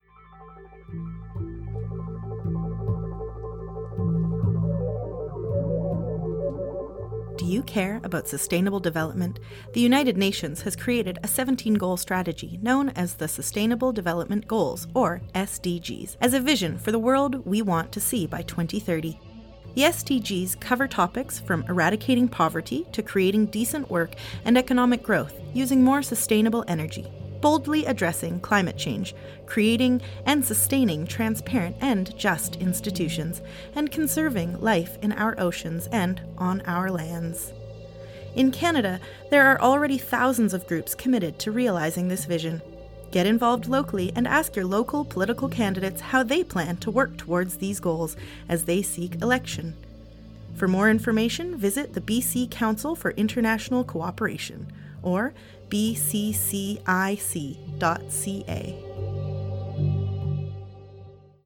Recording Location: CICK smithers
Type: PSA
128kbps Stereo